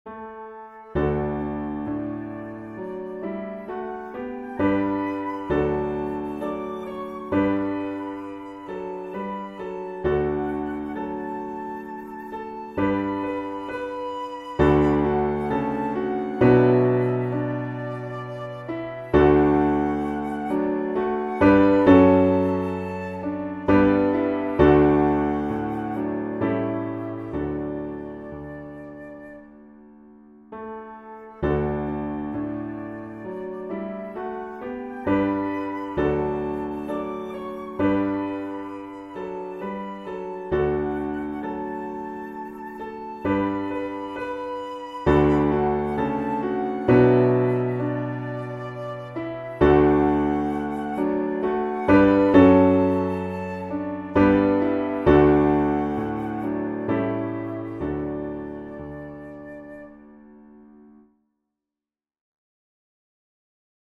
• Beginner-friendly key and range
traditional American folk song